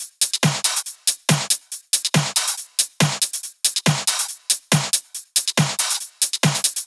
VFH2 140BPM Lectrotrance Kit 5.wav